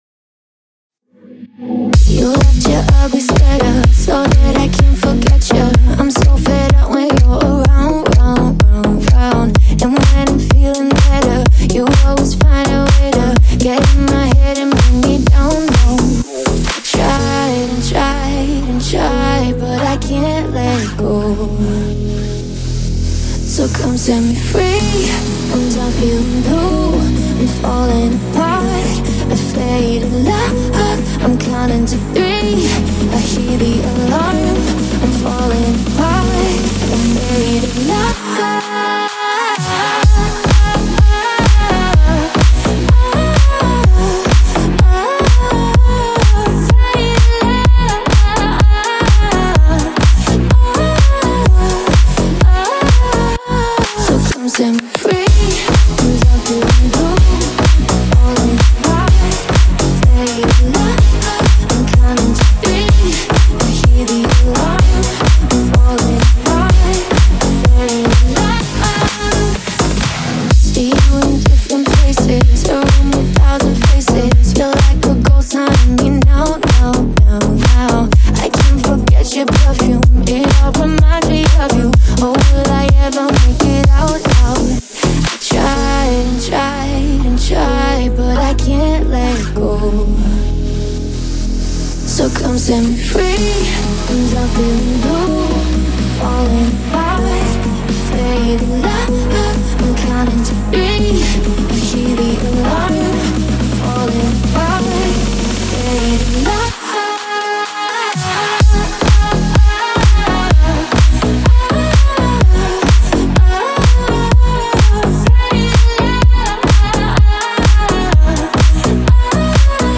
мощным вокалом